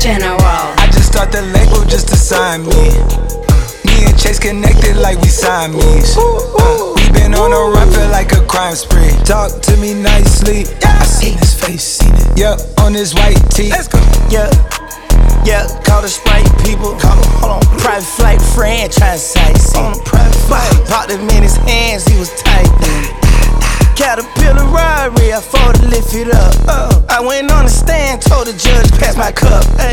• Hip-Hop/Rap